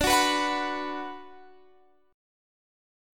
B5/D chord